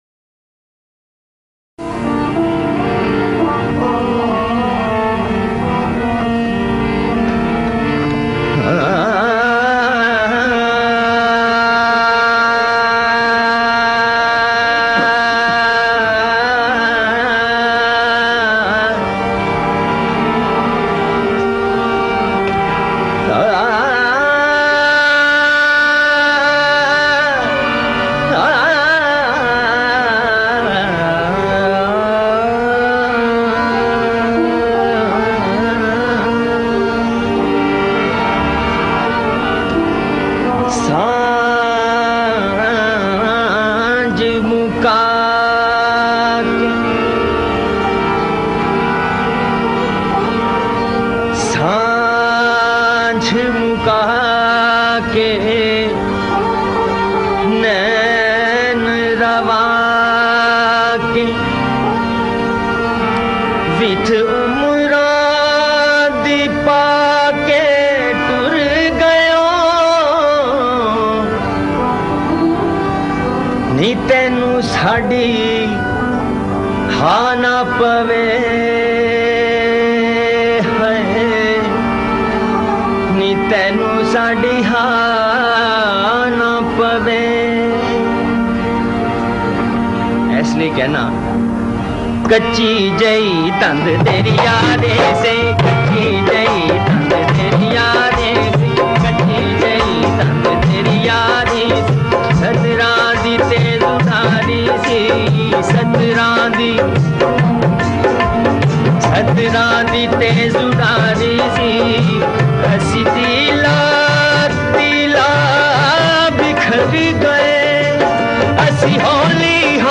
Ghazal, Punjabi Folk Songs